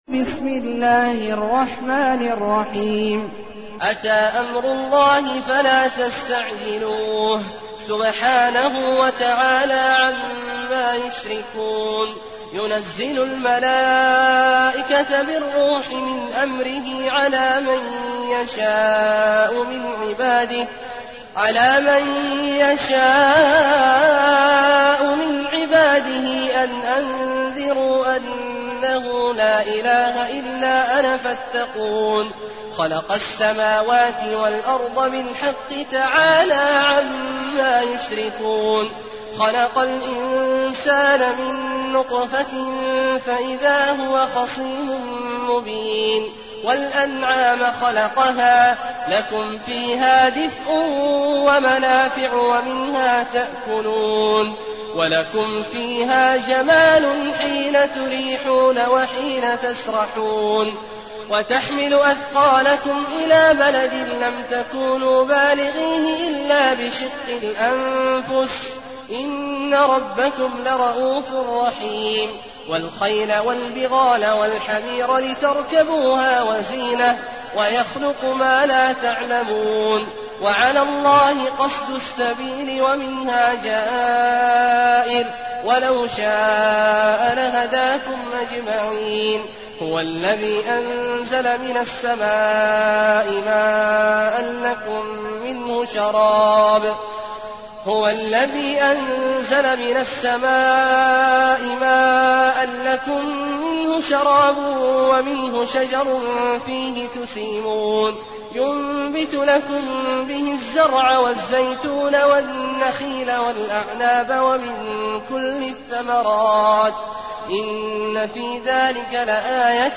المكان: المسجد النبوي الشيخ: فضيلة الشيخ عبدالله الجهني فضيلة الشيخ عبدالله الجهني النحل